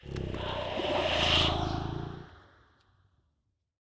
ambient_ominous2.ogg